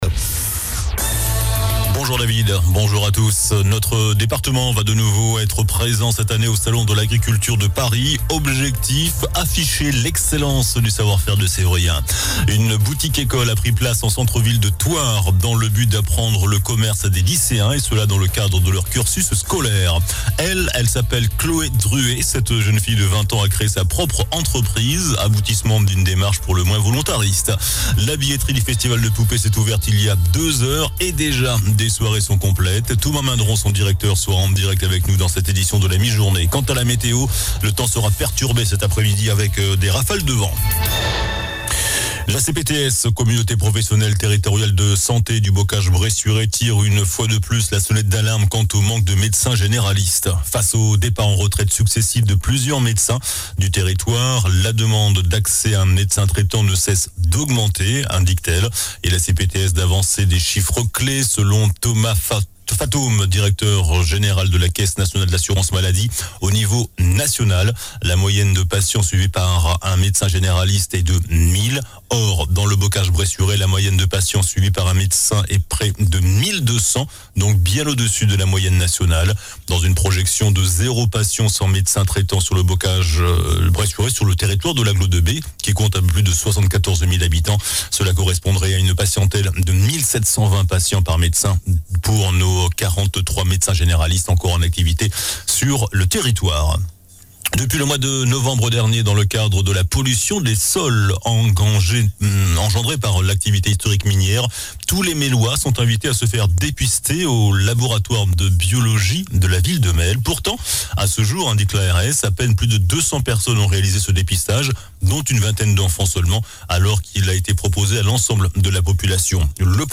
JOURNAL DU MERCREDI 21 FEVRIER ( MIDI )